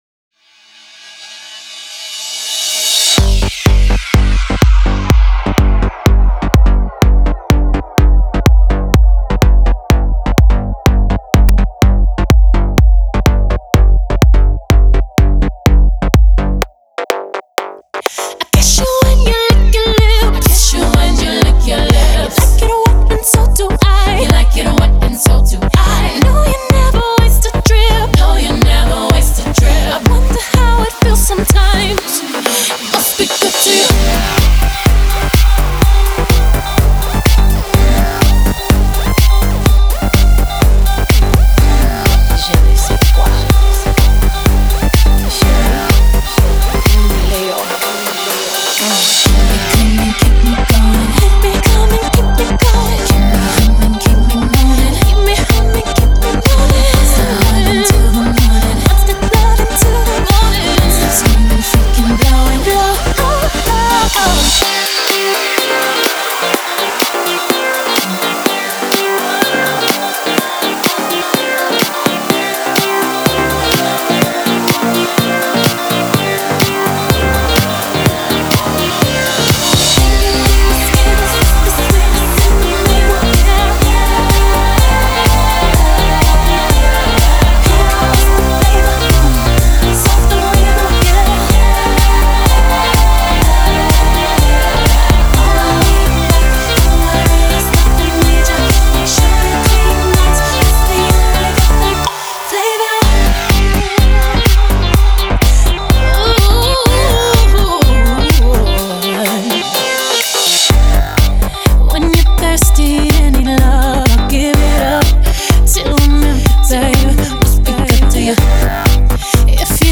electro house treatment